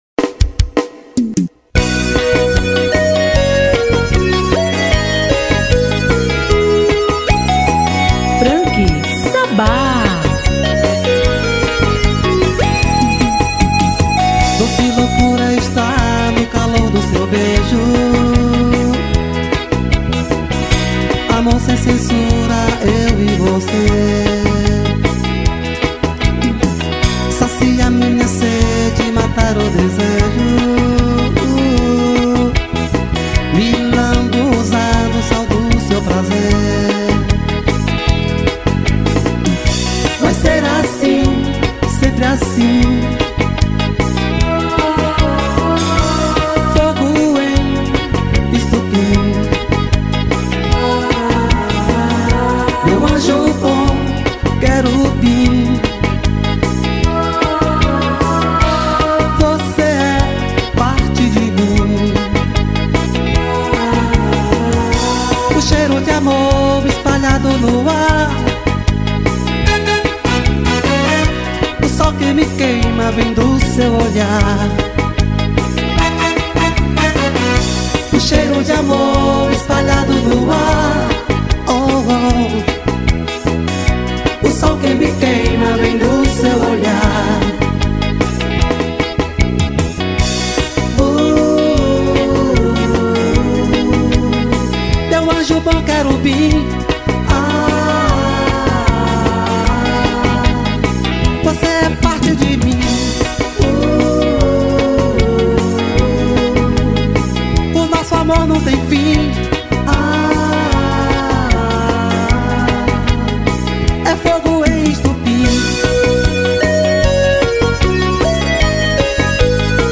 EstiloBrega